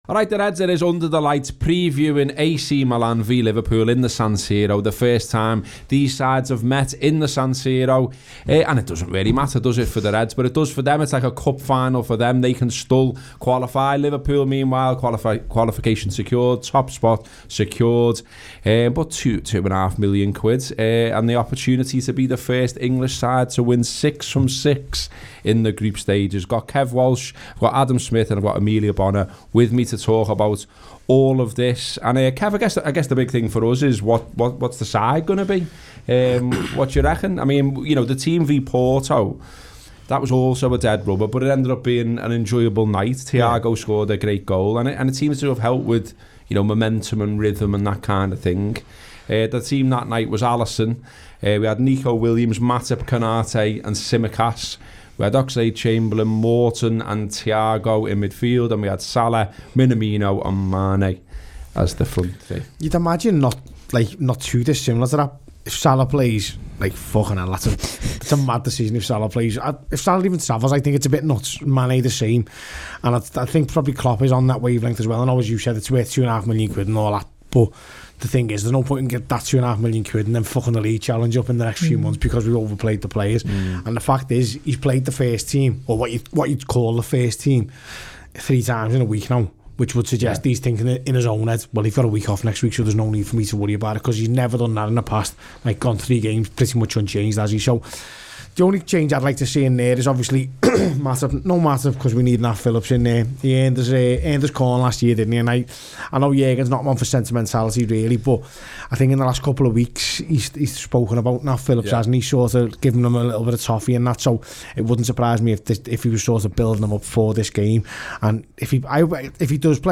Below is a clip from the show – subscribe for more on AC Milan v Liverpool…